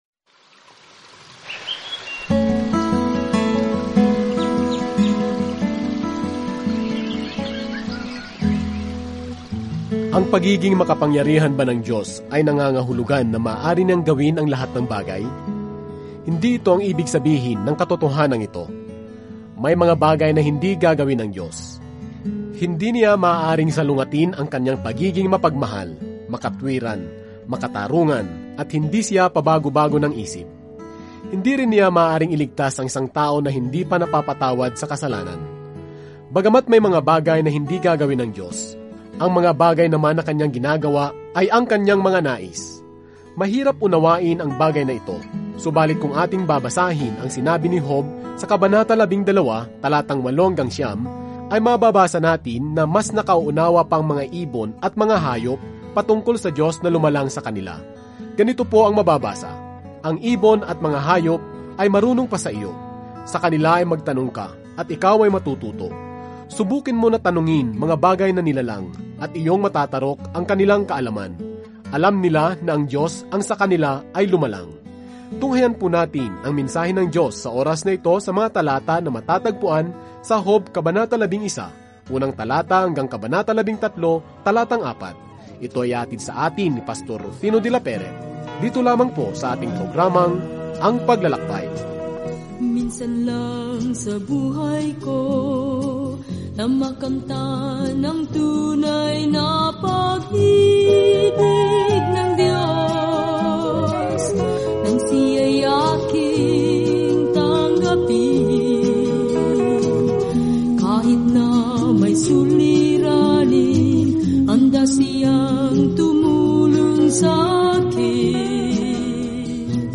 Banal na Kasulatan Job 11 Job 12 Job 13:1-4 Araw 8 Umpisahan ang Gabay na Ito Araw 10 Tungkol sa Gabay na ito Sa dramang ito sa langit at lupa, nakilala natin si Job, isang mabuting tao, na pinahintulutan ng Diyos na salakayin ni Satanas; lahat ay may napakaraming katanungan sa paligid kung bakit nangyayari ang mga masasamang bagay. Araw-araw na paglalakbay sa Job habang nakikinig ka sa audio study at nagbabasa ng mga piling talata mula sa salita ng Diyos.